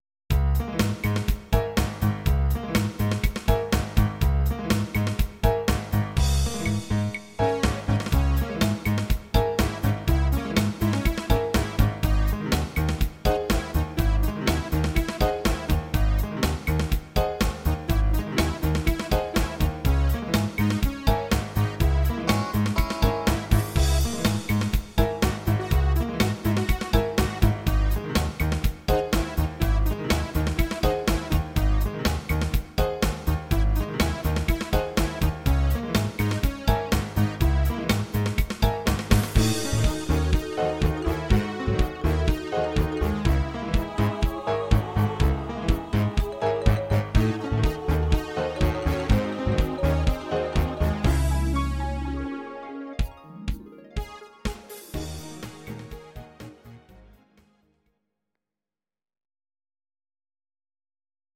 Audio Recordings based on Midi-files
Pop, Jazz/Big Band, 2010s